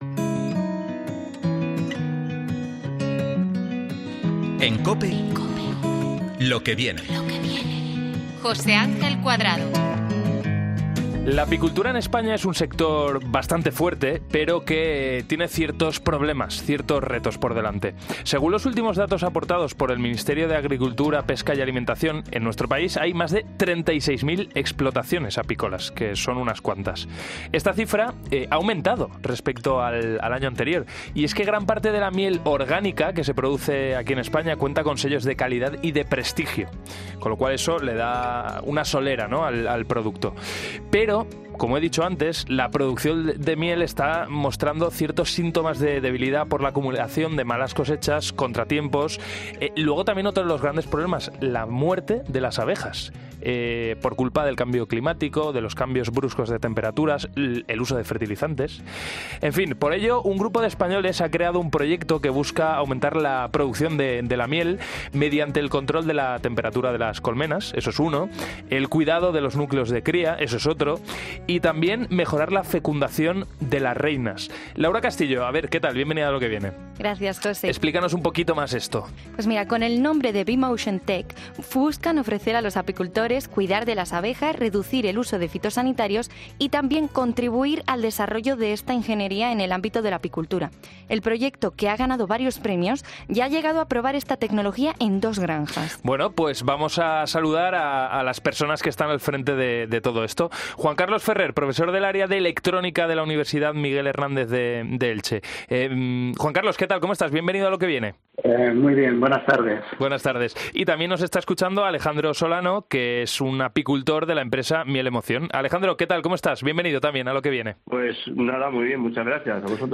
Un profesor de electrónica y dos apicultores han explicado en 'Lo que Viene' cómo las abejas y la apicultura pueden cambiar de situación gracias a...